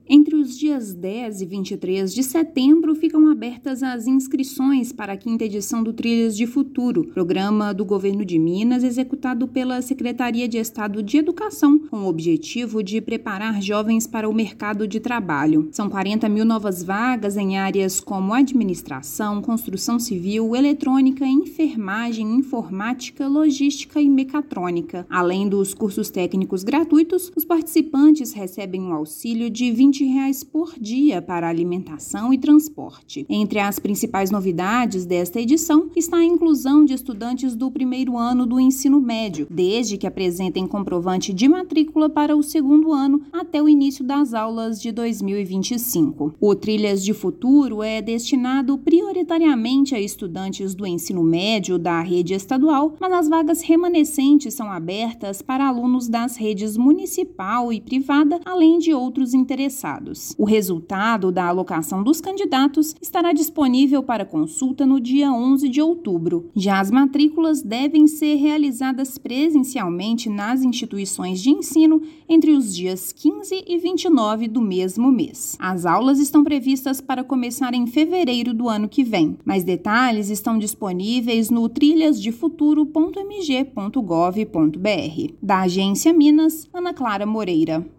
Iniciativa oferece 40 mil novas vagas para cursos profissionalizantes gratuitos; início das aulas está previsto para fevereiro de 2025. Ouça matéria de rádio.